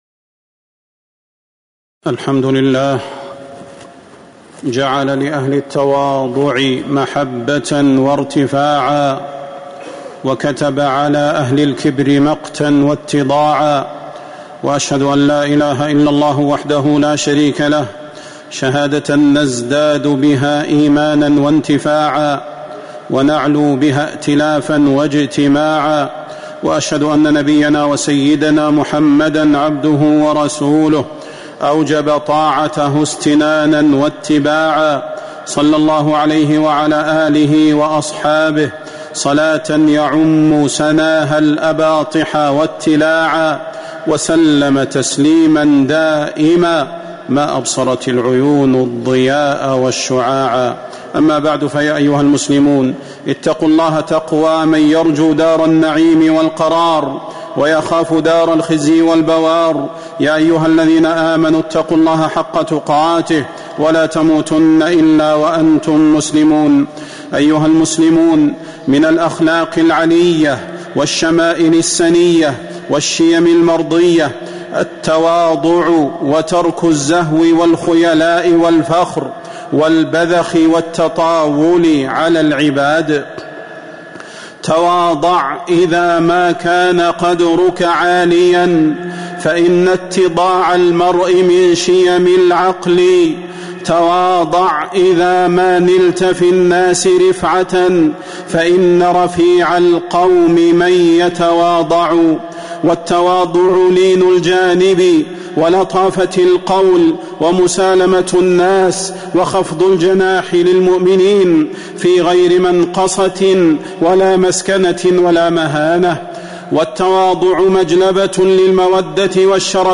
تاريخ النشر ٨ ربيع الثاني ١٤٤٦ هـ المكان: المسجد النبوي الشيخ: فضيلة الشيخ د. صلاح بن محمد البدير فضيلة الشيخ د. صلاح بن محمد البدير التواضع